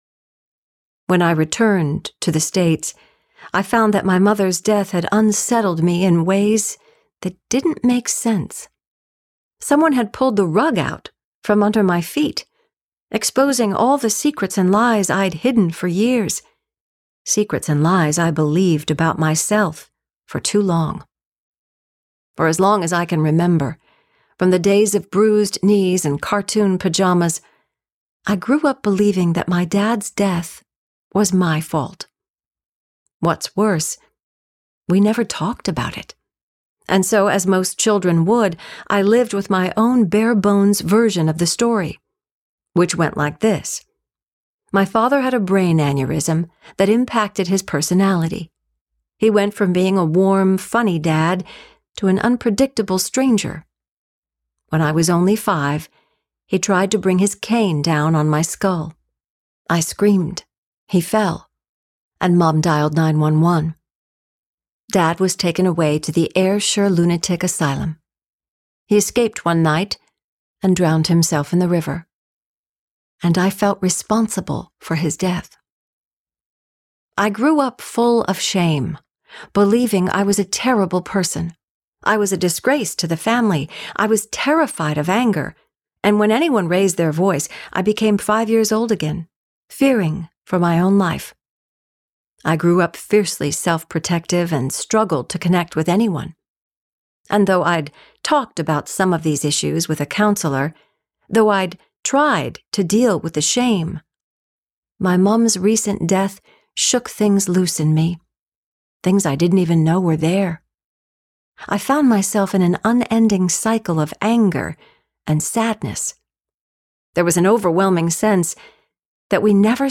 In the Middle of the Mess Audiobook